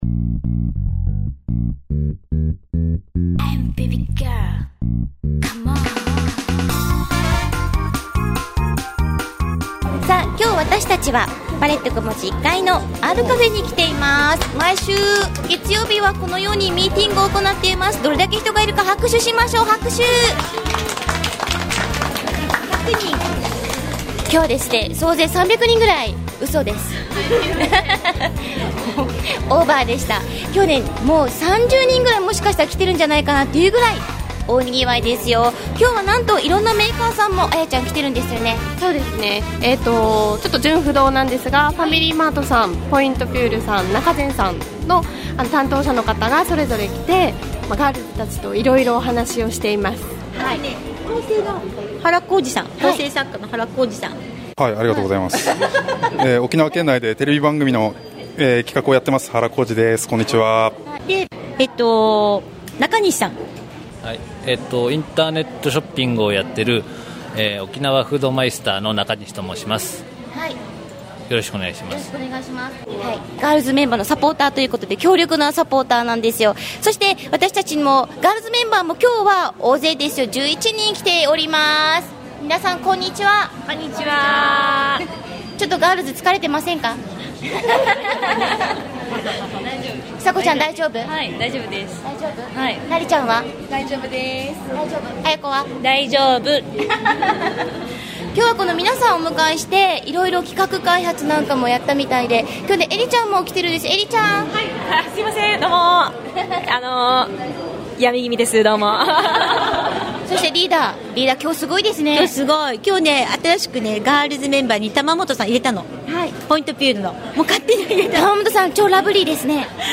今回はいつものgirls' memberのおしゃべりに、男性陣も初参加 仲善 さん、 ファミリーマート さん、 ポイントピュール さん、いらっしゃいませ 総勢２０名！